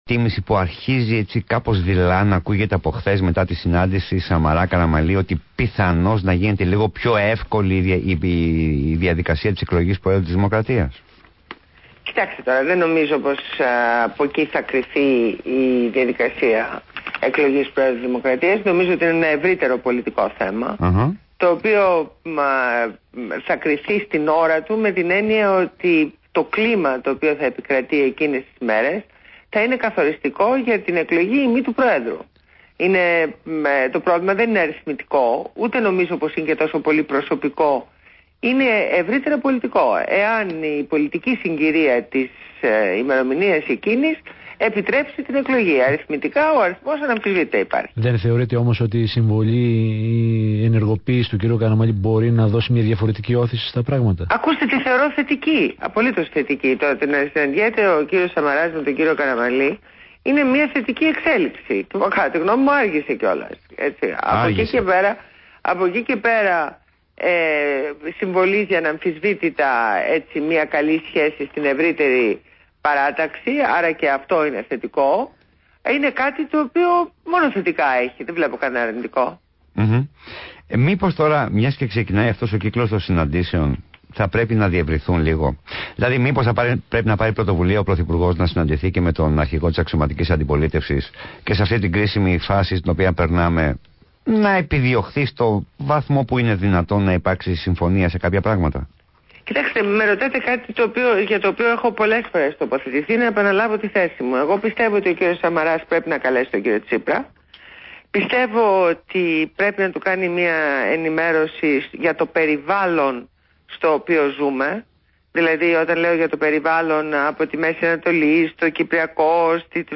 Συνέντευξη στο ραδιόφωνο ΒΗΜΑfm